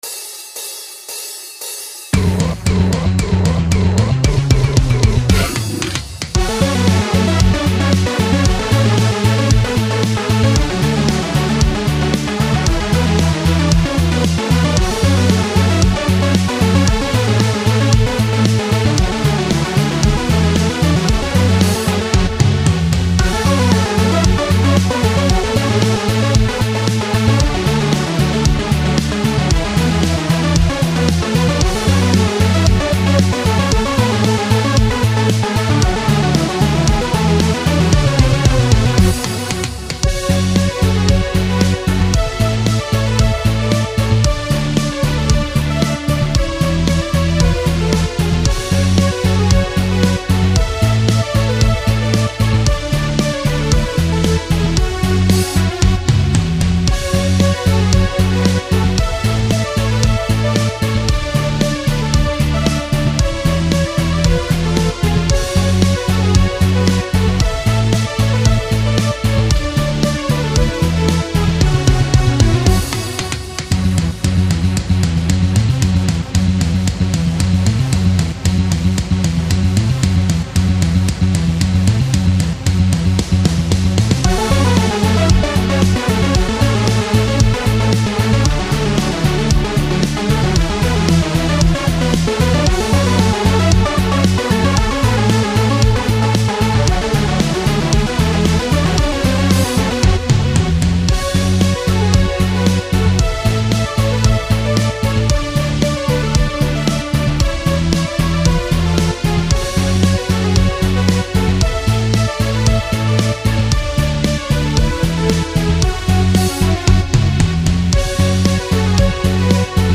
【用途/イメージ】　おもしろ動画　ダイジェスト映像　可笑しい　楽しい　笑い